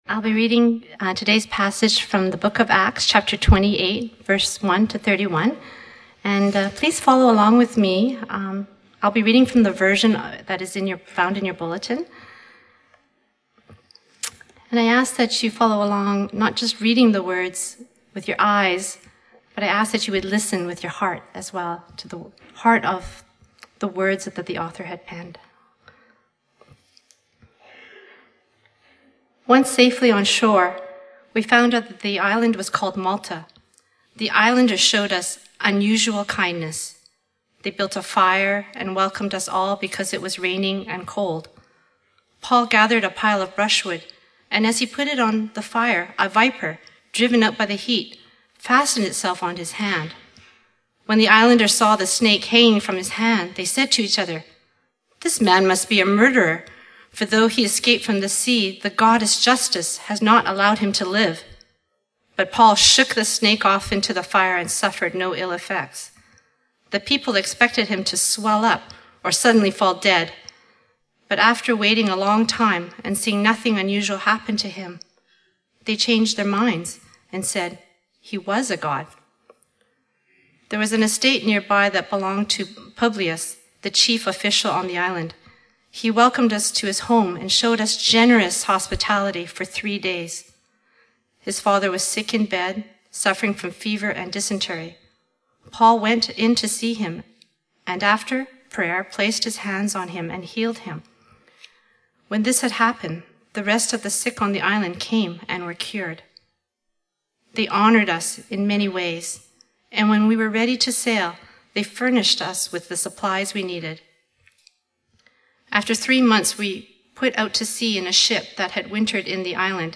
Fraser Lands Church Worship Service & Sermon Podcast | Fraser Lands Church